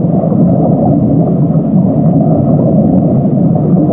underwater.wav